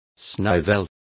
Complete with pronunciation of the translation of snivels.